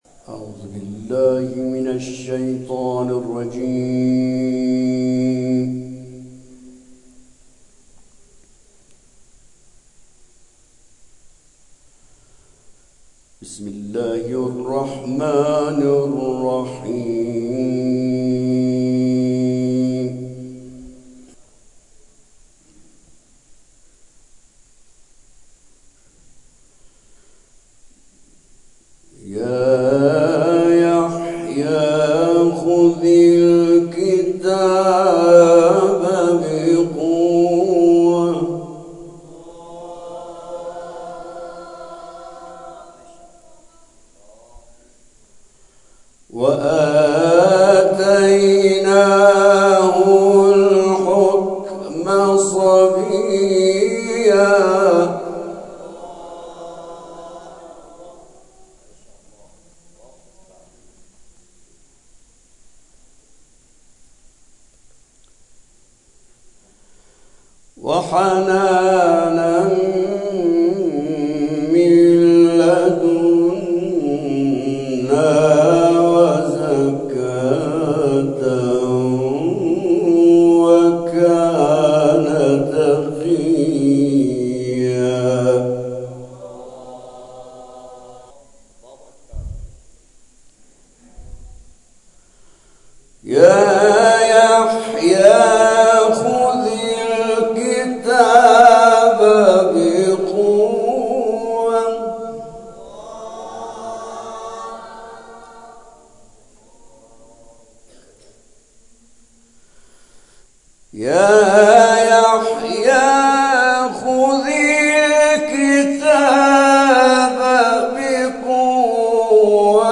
در پایان، تلاوت‌های این جلسه قرآن ارائه می‌شود.